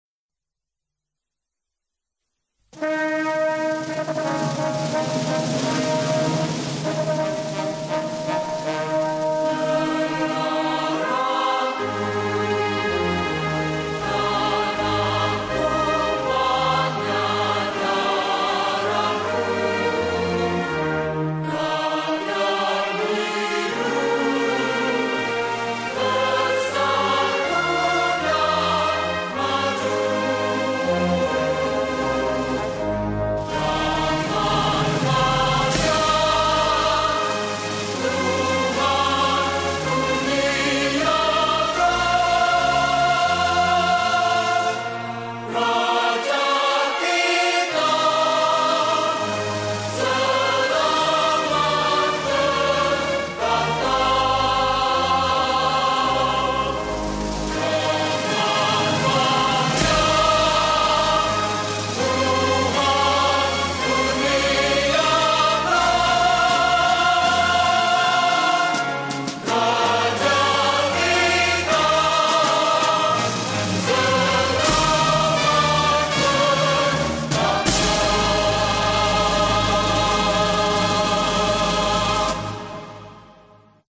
Patriotic Songs